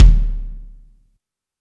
INSKICK18 -L.wav